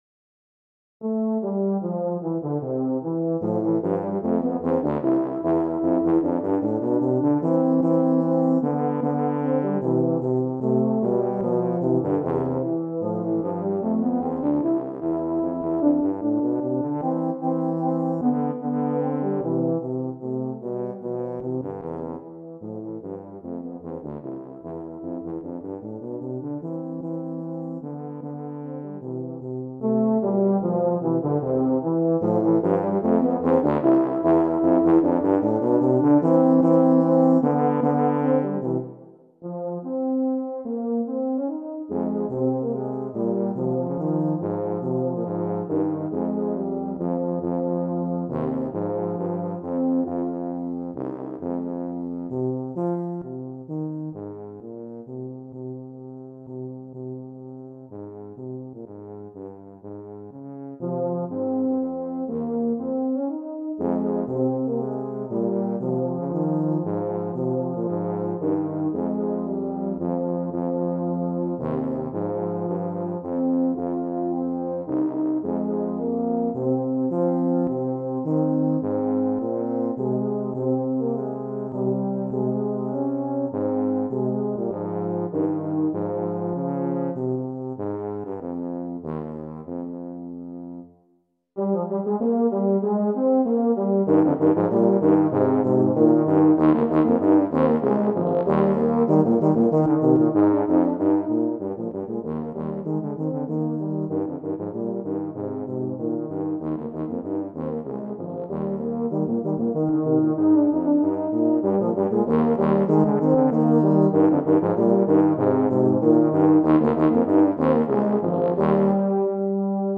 Voicing: Tuba Duet